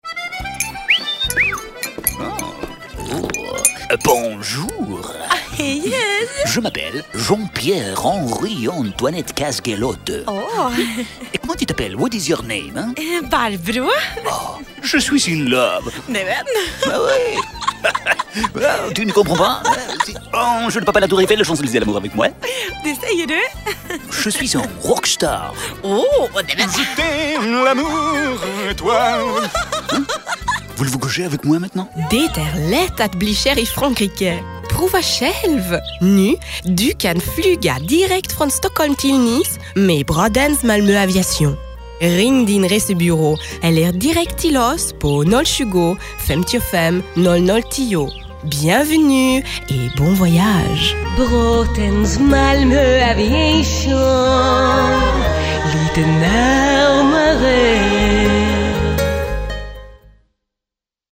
"Flygmaskinen och Fransmannen" Lo-Fi